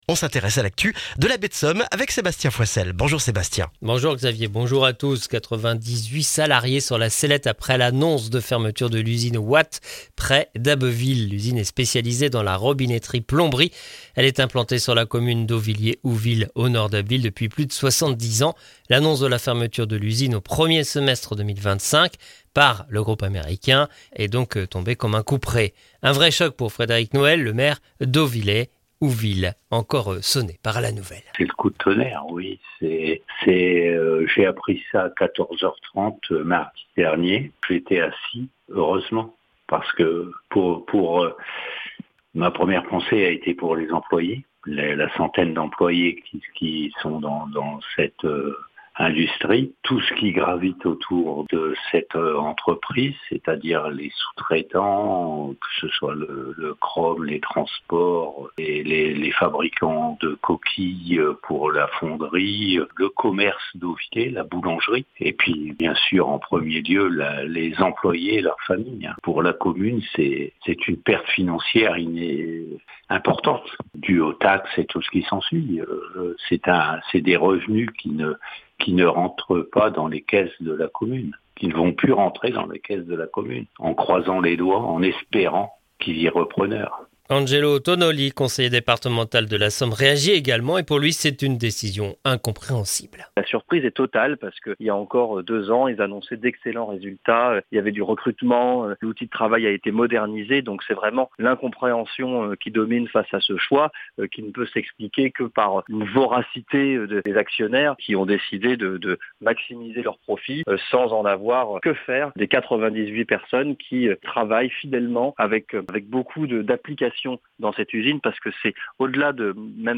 Le journal du vendredi 4 octobre en Baie de Somme et dans la région d'Abbeville